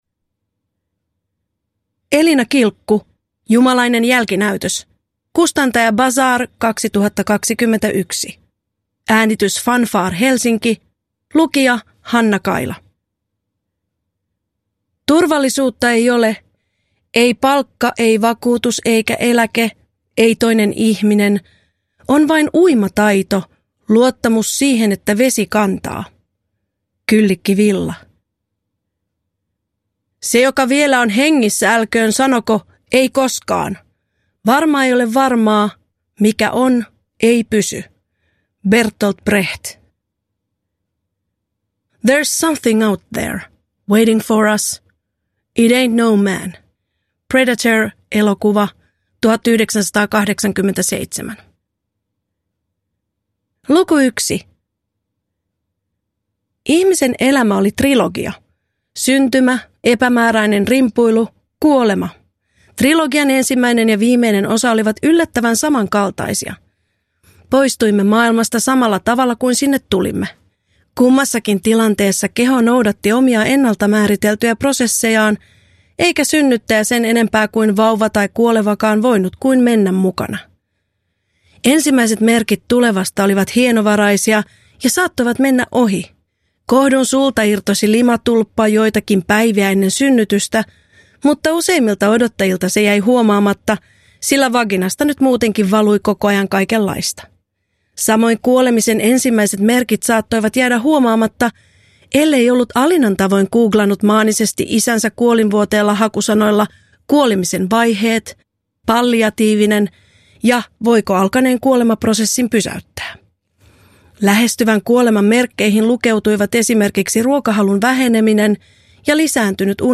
Jumalainen jälkinäytös – Ljudbok – Laddas ner